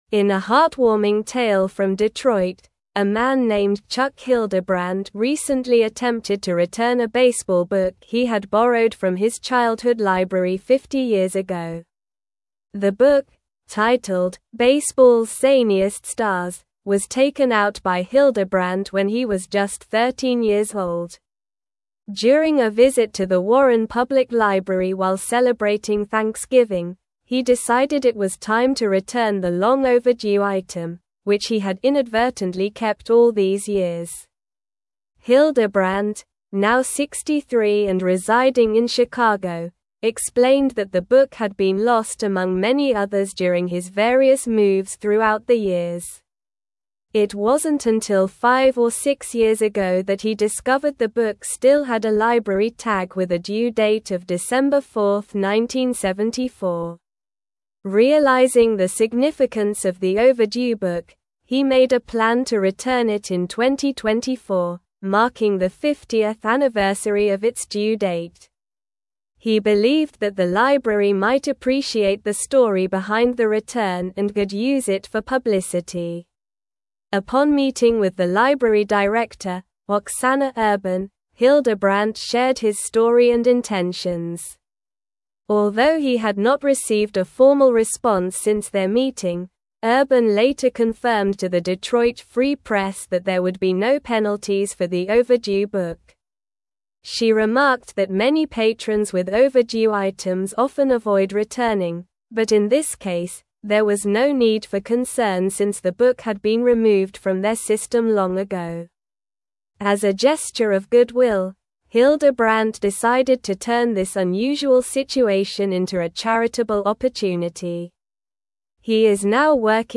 Slow
English-Newsroom-Advanced-SLOW-Reading-Man-Returns-Overdue-Library-Book-After-50-Years.mp3